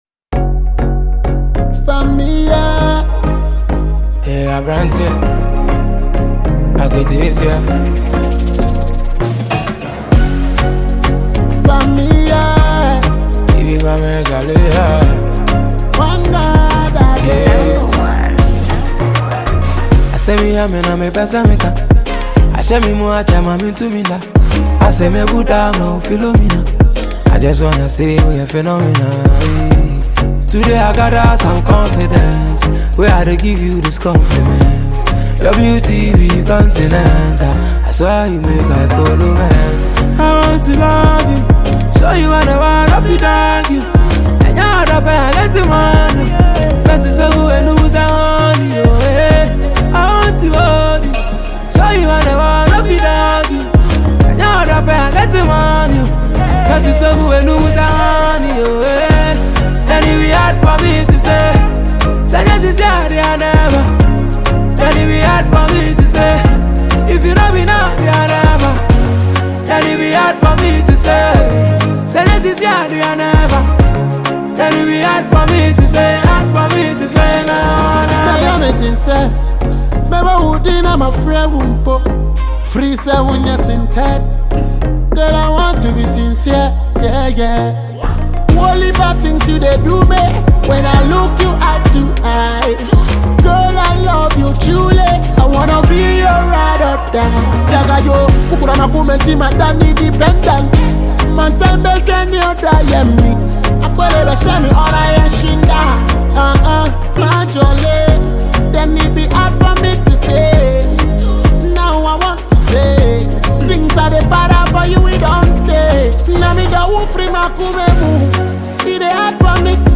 deeply emotional and inspirational new single
Genre: Highlife / Afro-fusion